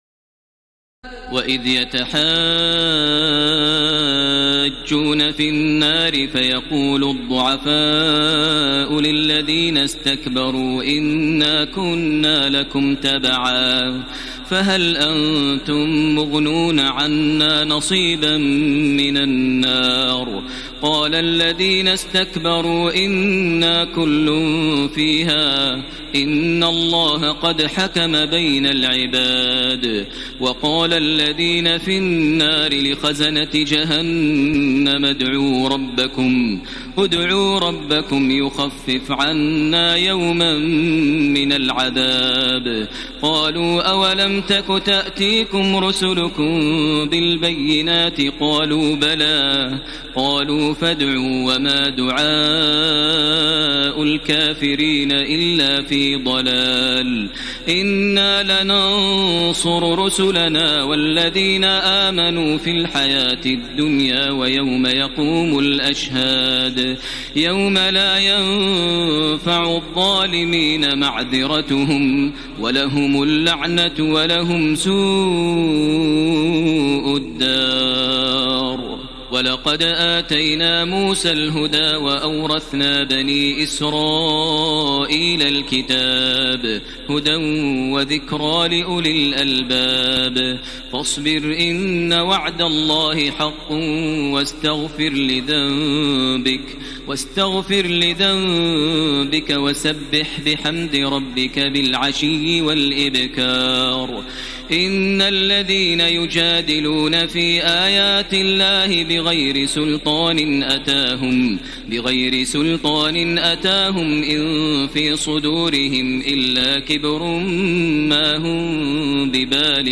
تراويح ليلة 23 رمضان 1433هـ من سور غافر (47-85) وفصلت (1-46) Taraweeh 23 st night Ramadan 1433H from Surah Ghaafir and Fussilat > تراويح الحرم المكي عام 1433 🕋 > التراويح - تلاوات الحرمين